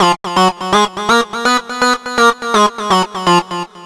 Arp Lead_124_F.wav